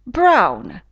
brown [braun]